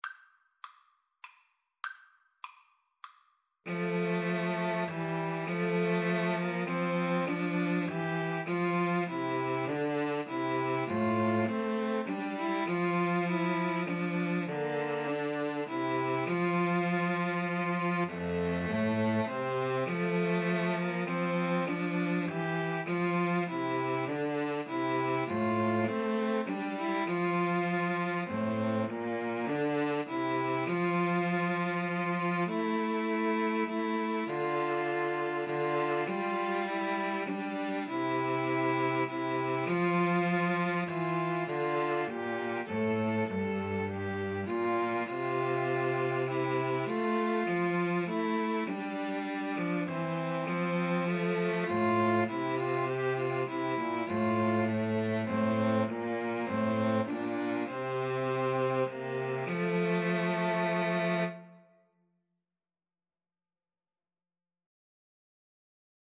3/4 (View more 3/4 Music)
Classical (View more Classical 2-Violins-Cello Music)